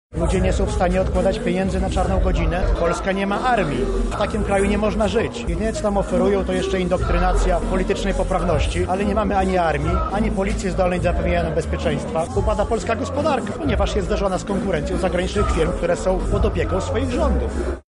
„System podatkowy niszczy w naszym kraju wielu przedsiębiorców”mówi kandydat Ruchu Narodowego na urząd prezydenta.